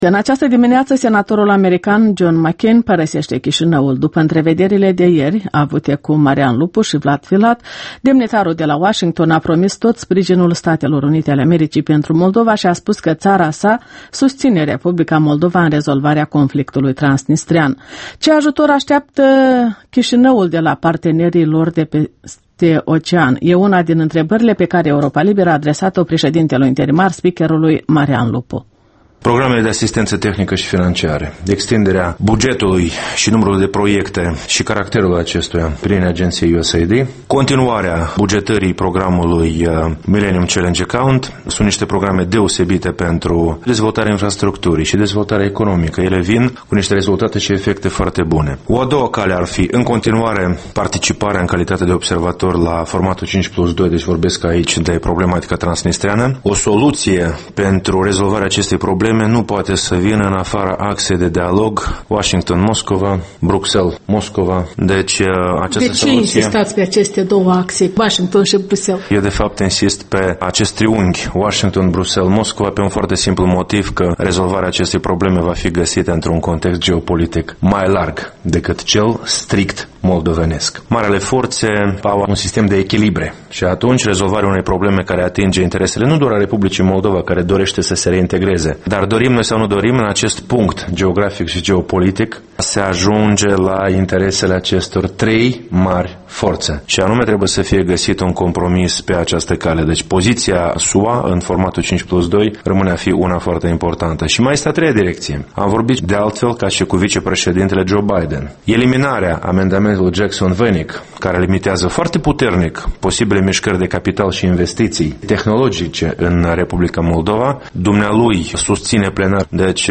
Interviul matinal EL: cu președintele interimar Marian Lupu